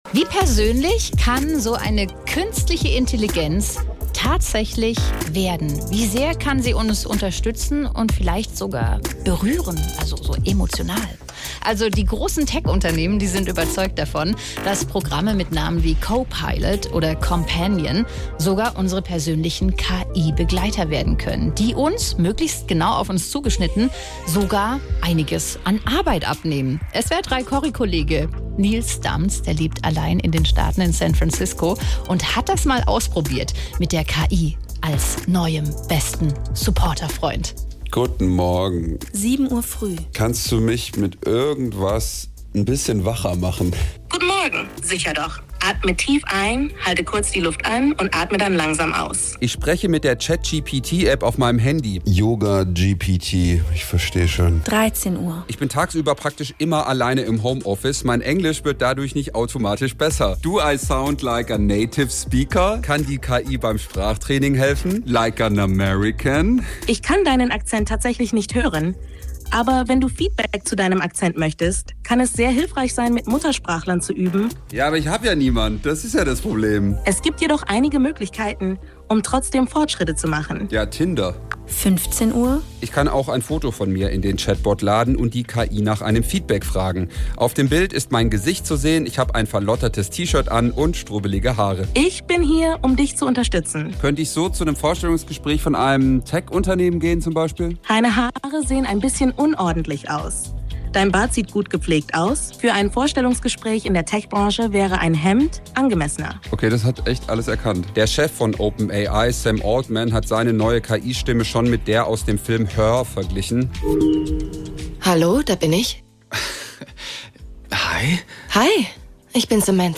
Nachrichten Gespräch mit einer KI